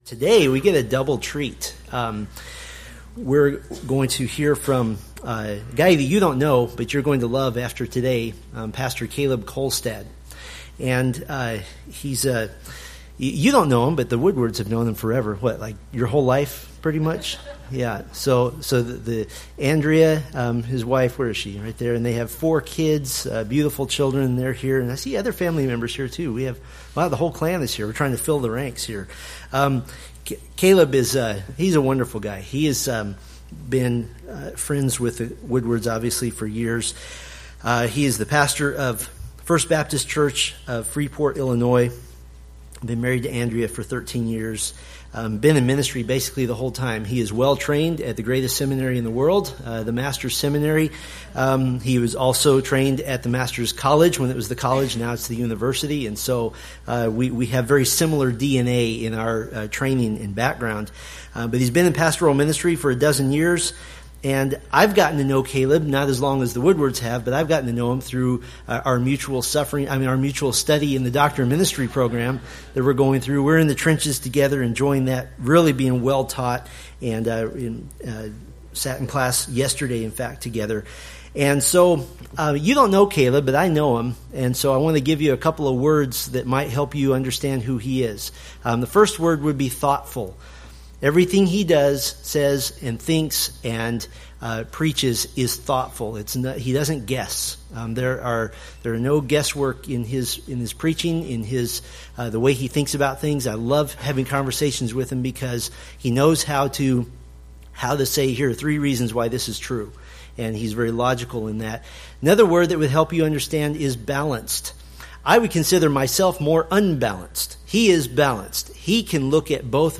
From the Various 2017 sermon series.